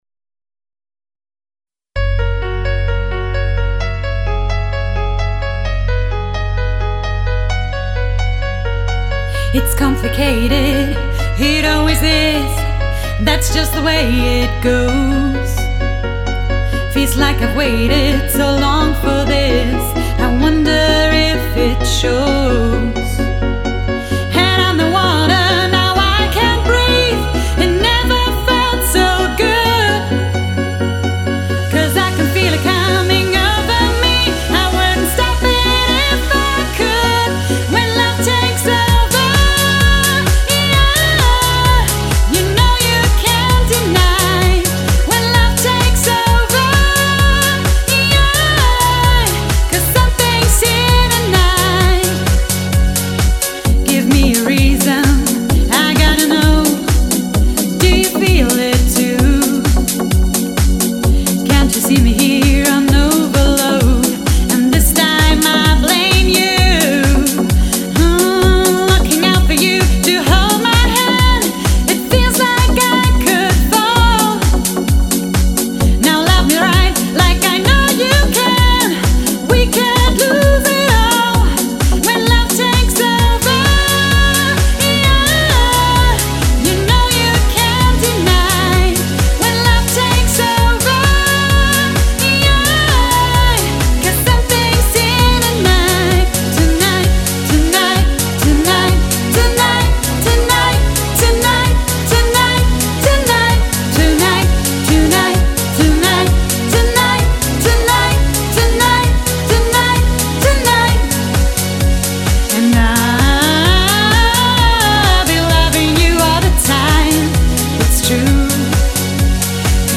Dance-Hit